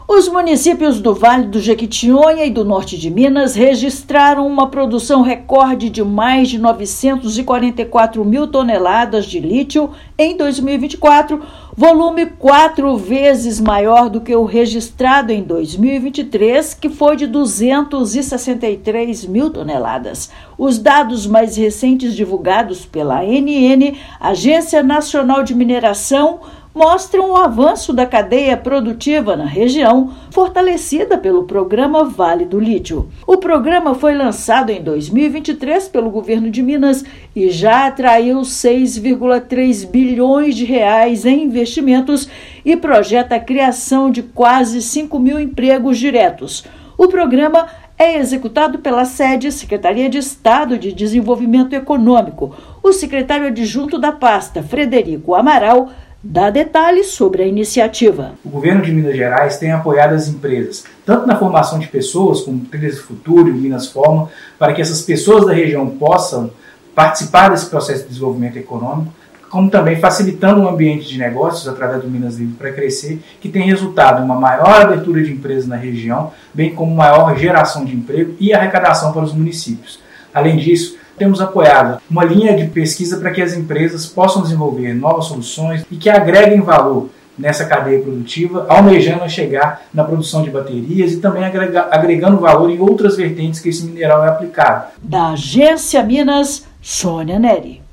Produção de lítio bate recorde em 2024 e impulsiona geração de empregos e abertura de novas empresas nos municípios do Vale do Jequitinhonha e Norte de Minas. Ouça matéria de rádio.